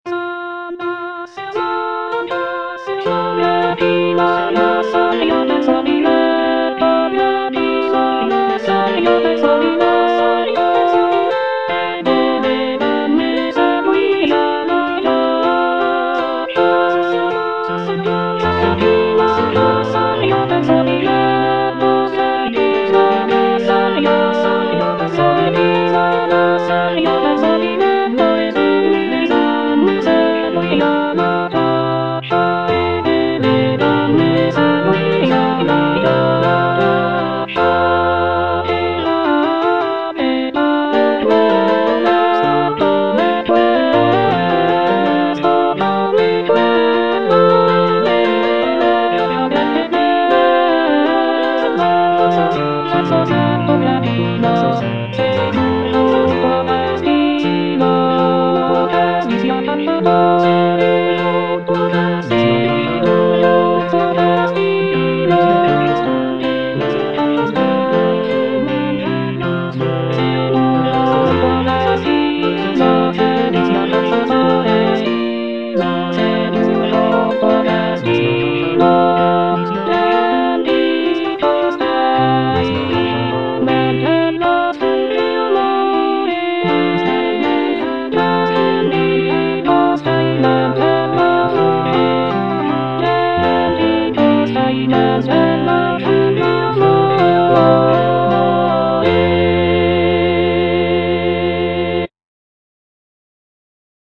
"S'andasse Amor a caccia" is a madrigal composed by Claudio Monteverdi, an Italian composer from the late Renaissance period.
C. MONTEVERDI - S'ANDASSE AMOR A CACCIA Alto (Emphasised voice and other voices) Ads stop: